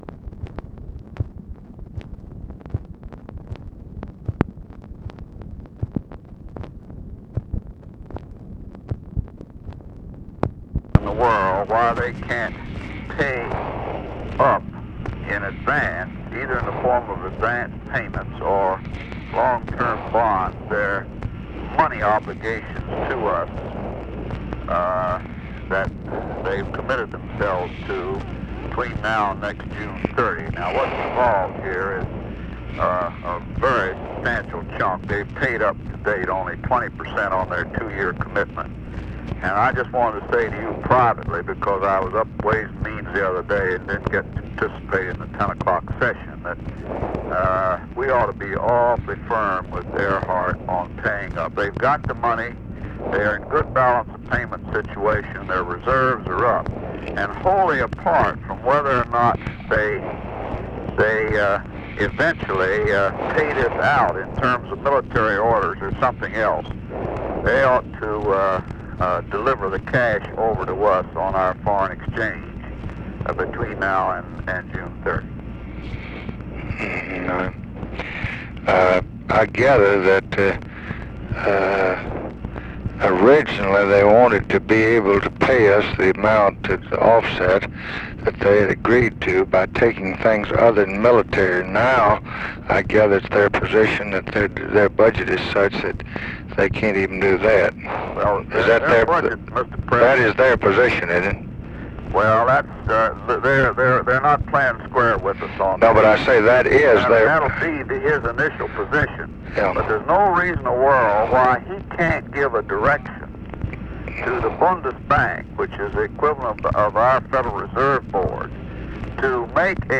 Conversation with HENRY FOWLER, September 24, 1966
Secret White House Tapes